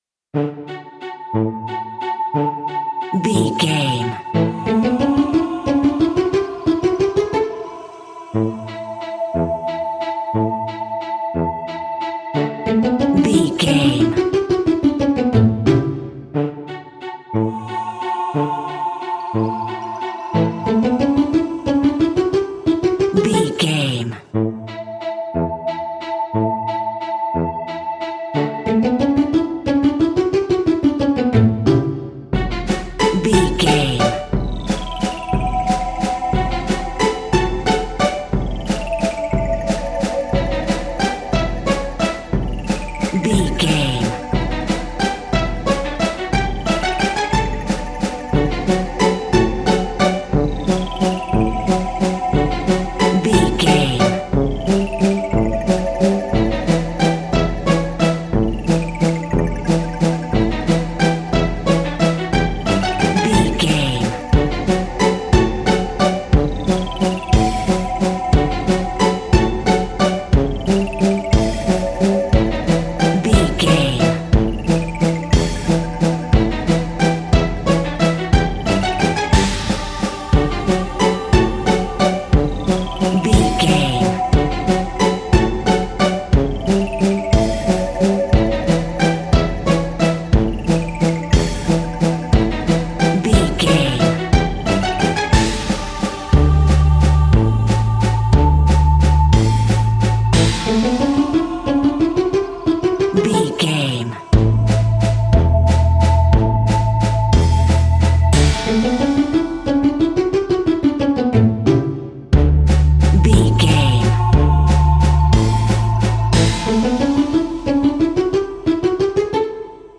Aeolian/Minor
D
scary
ominous
suspense
eerie
playful
strings
synthesiser
brass
perscussion
horror music